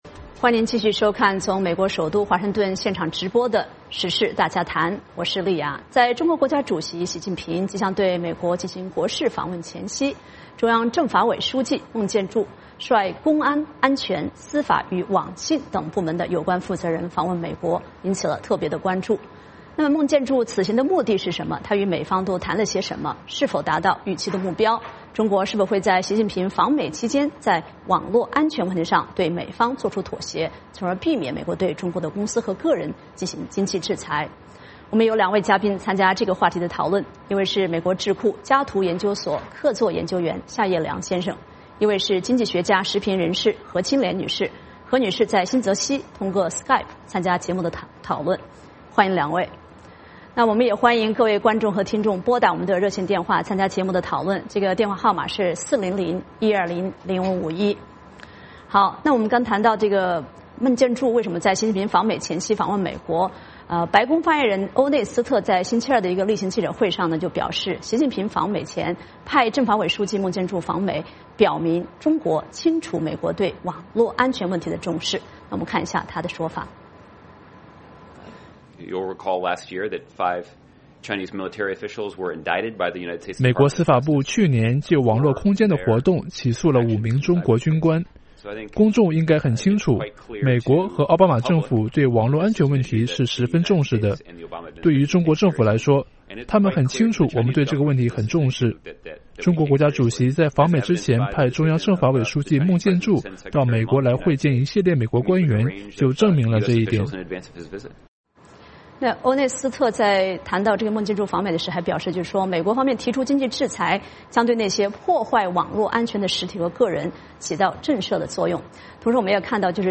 我们有二位嘉宾参加这个话题的讨论